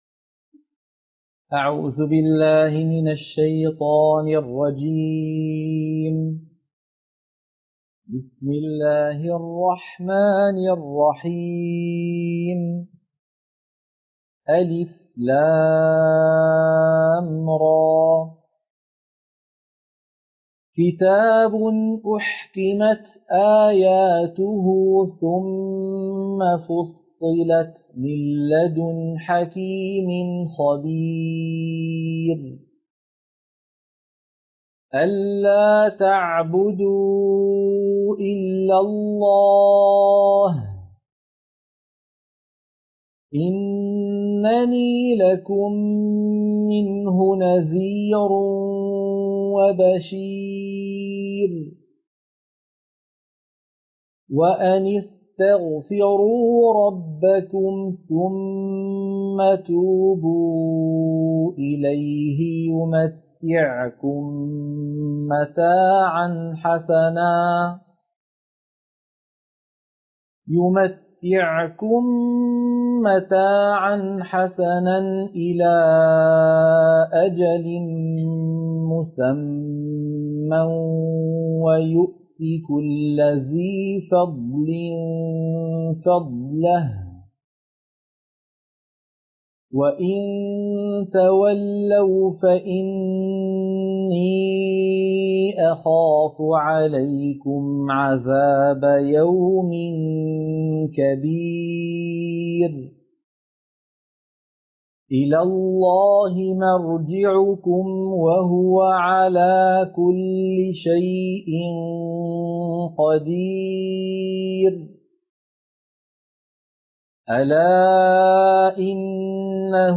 سورة هود - القراءة المنهجية